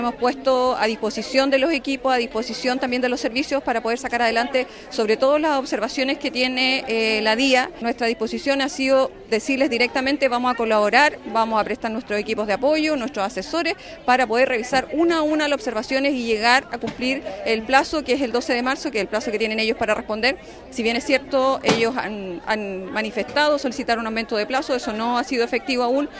En el lugar también estuvo presente la directora regional del Serviu, Nebenka Donoso, que aseguró que han puesto a todos sus equipos a disposición para trabajar con la entidad y responder de manera conjunta las observaciones, teniendo como plazo el próximo 12 de marzo.